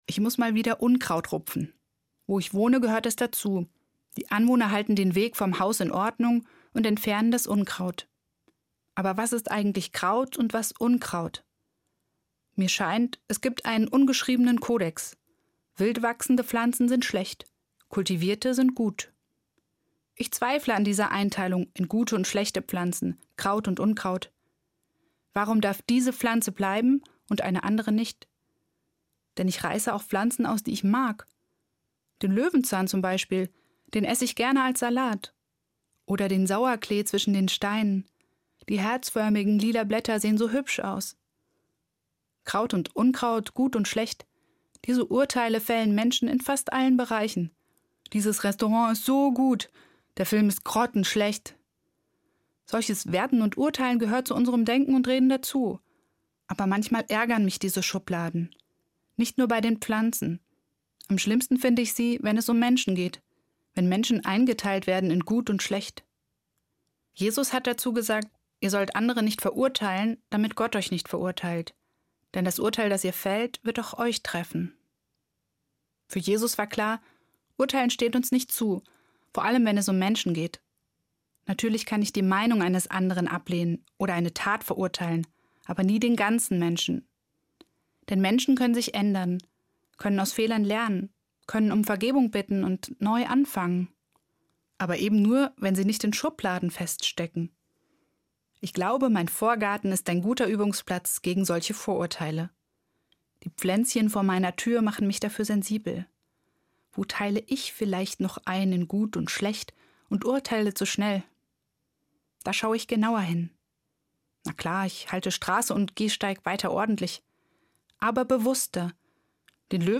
Evangelische Pfarrerin, Gießen